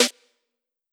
Snare (Cameras).wav